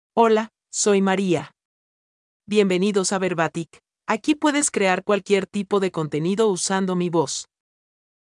Maria — Female Spanish (Costa Rica) AI Voice | TTS, Voice Cloning & Video | Verbatik AI
Maria is a female AI voice for Spanish (Costa Rica).
Voice sample
Female
Spanish (Costa Rica)
Maria delivers clear pronunciation with authentic Costa Rica Spanish intonation, making your content sound professionally produced.